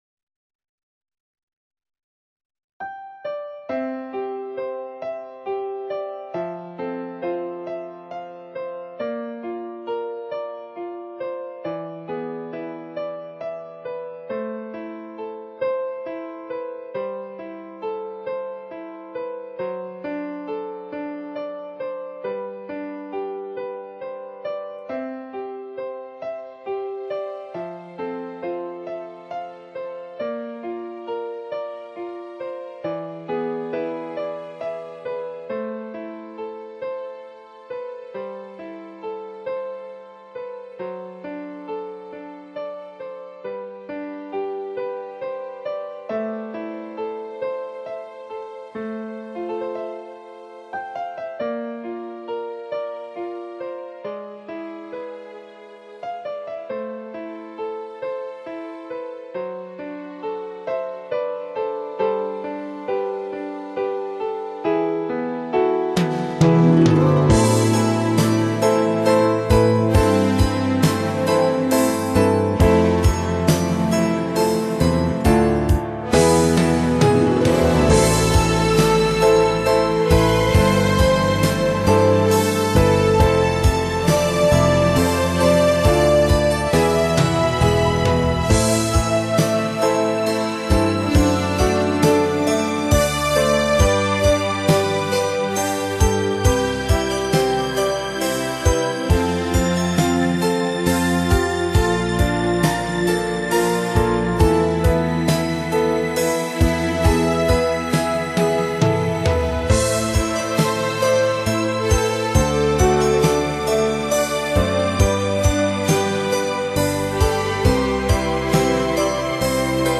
Digitally remastered using HDCD technology.